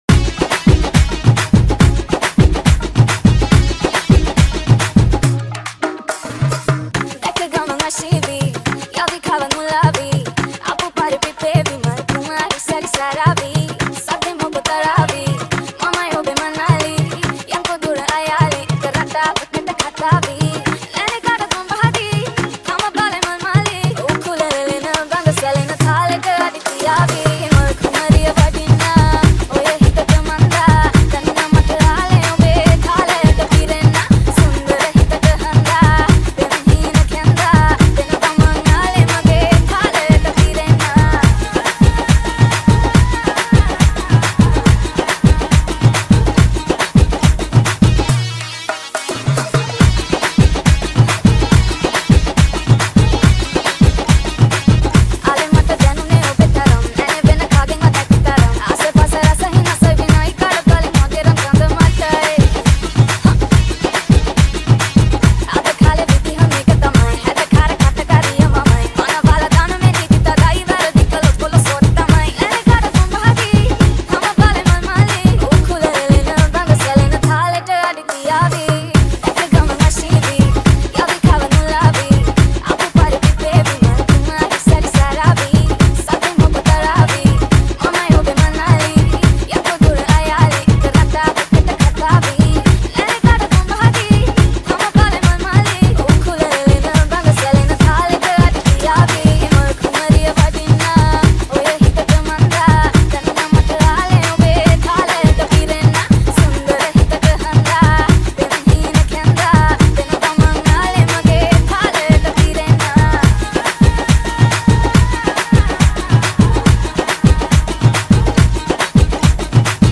Releted Files Of Sinhala New Dj Remix Single Mp3 Songs